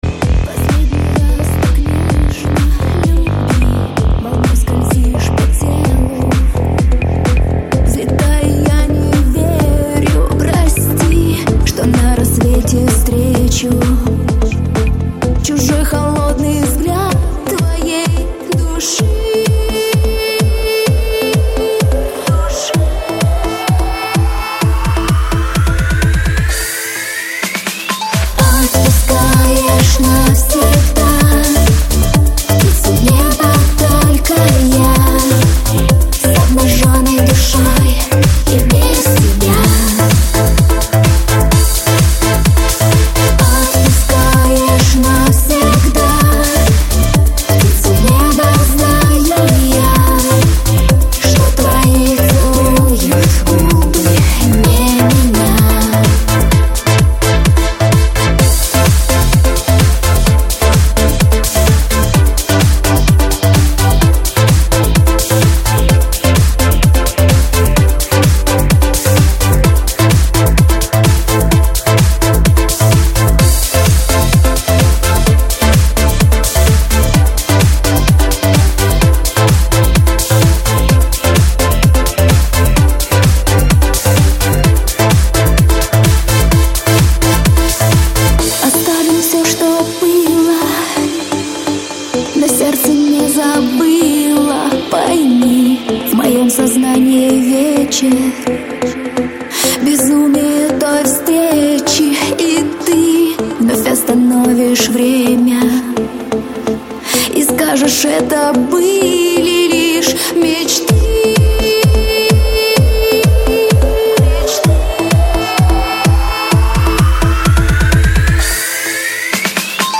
Стиль: Dance